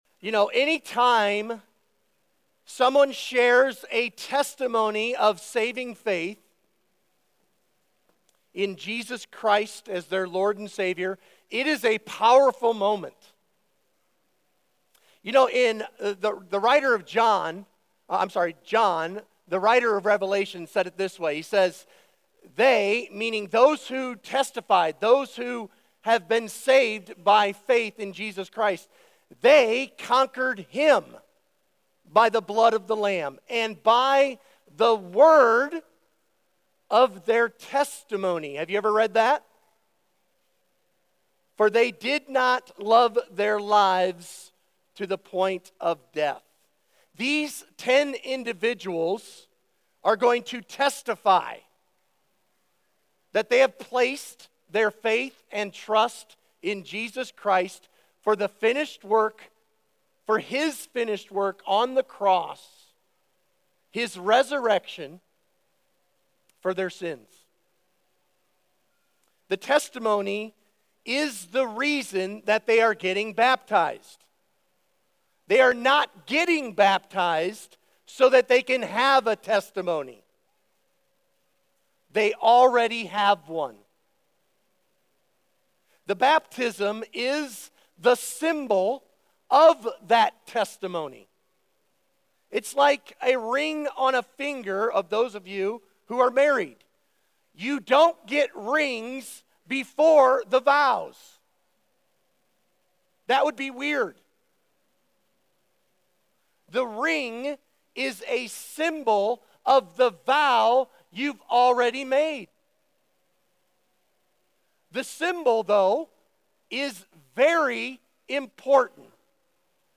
Thanksgiving Baptism Sunday (November 24, 2014) | High Pointe Church